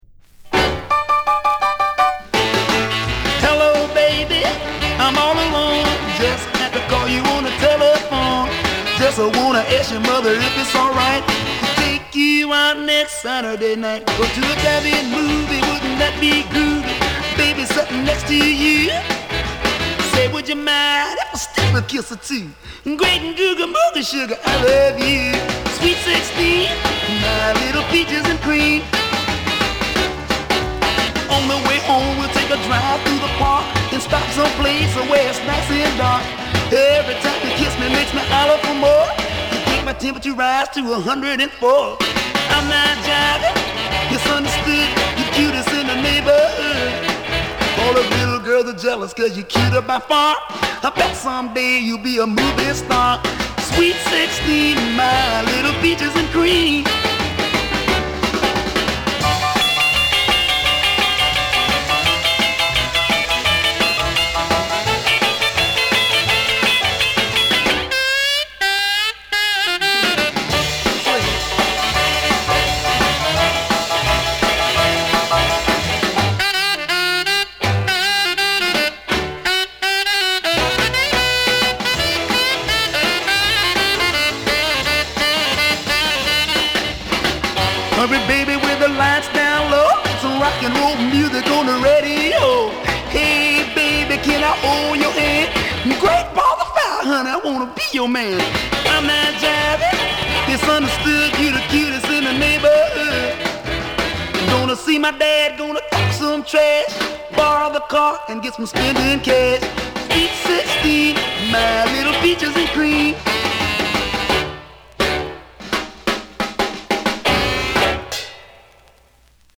人懐っこいヴォーカルが魅力的なブラック・ロッカー。
エンディング付近にプレスミスgaあり（両面とも同じ箇所にキズのようなあと）、音に影響します（写真3）。